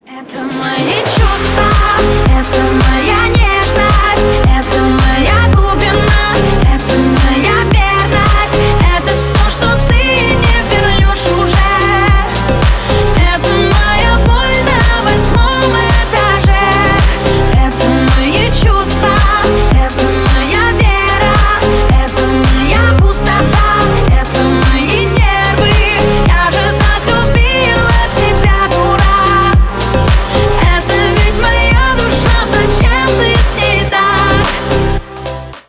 на русском на бывшего грустные